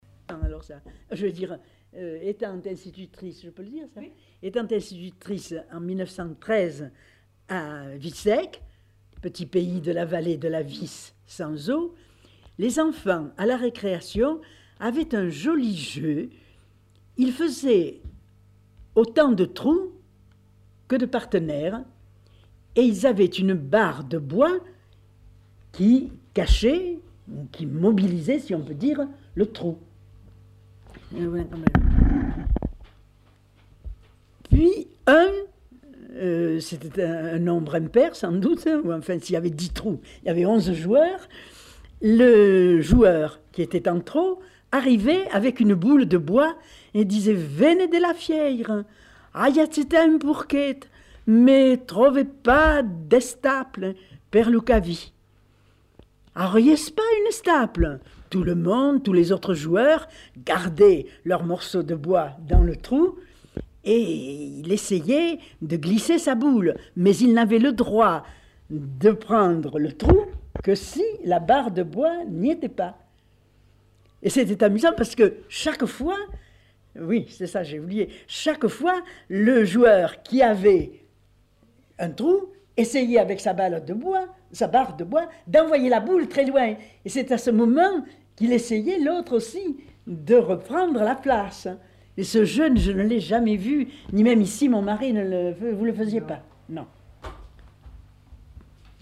Lieu : Arrigas
Genre : témoignage thématique